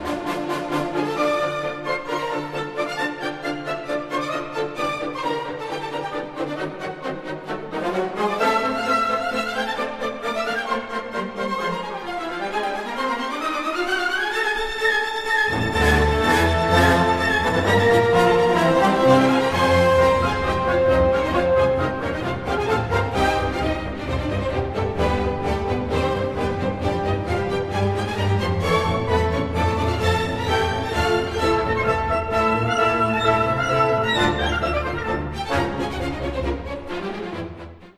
The symphony is a shining example of Dvořák’s mature style, which incorporates his lifelong love of Bohemian folk music within the traditional Germanic symphonic structures. The first movement, Allegro non tanto, has frequently been compared with the opening of Brahms’ Second Symphony, not only in that they share the key of D major but also in the pastoral quality of the themes and the bucolic writing for horns and woodwinds.
The Adagio is one of Dvořák’s most idyllic slow movements.